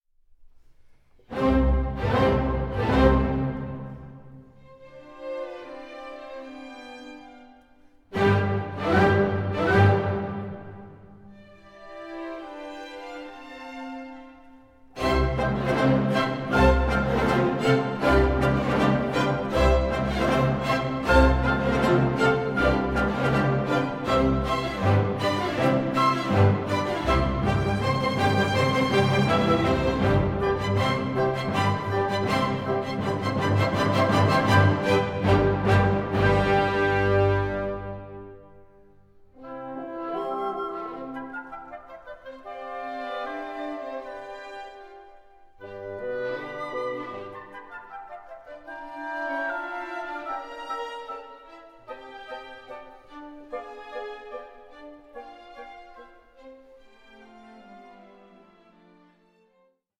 Andante cantabile 11:00